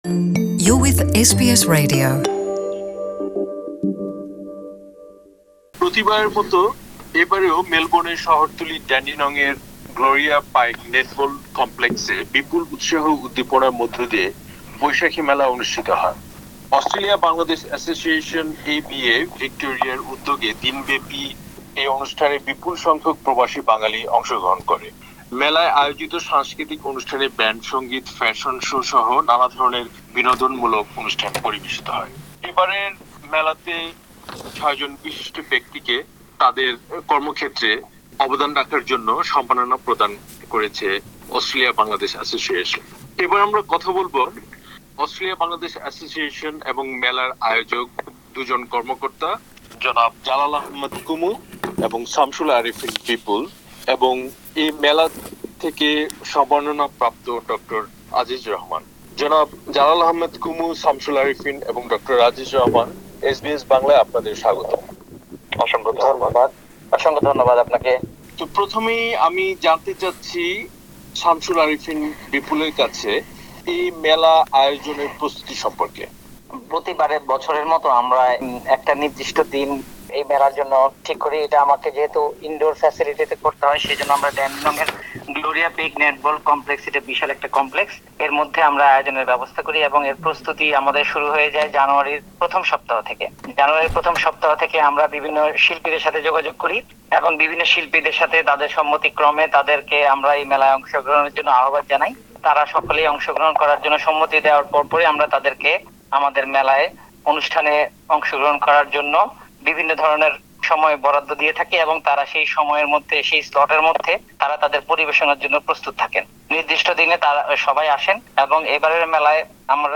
Dandenong Boishakhi Mela organisers talk to SBS Bangla